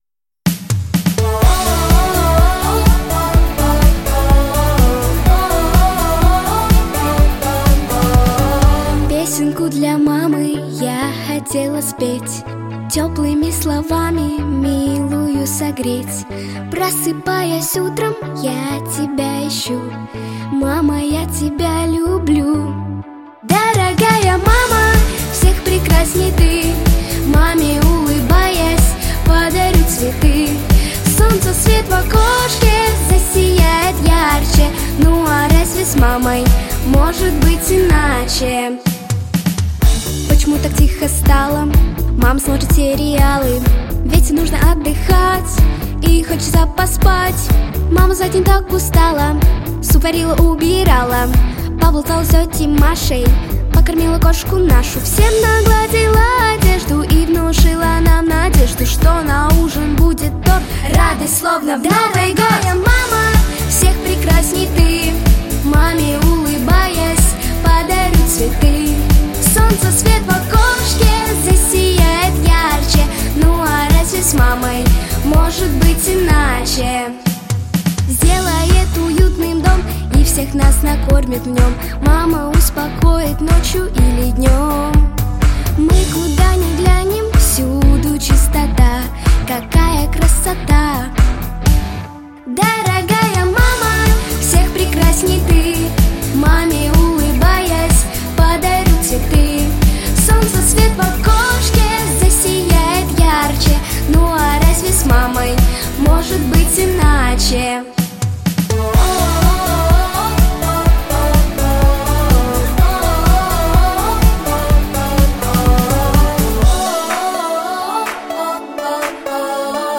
🎶 Детские песни / О близких людях / Песни про маму